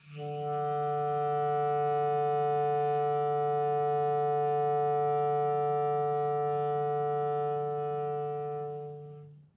DCClar_susLong_D2_v2_rr1_sum.wav